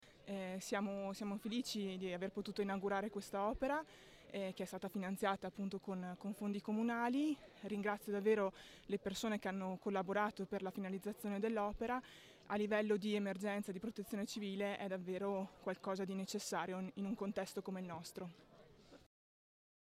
sindaco_PELLIZZARI_su_piazzola.mp3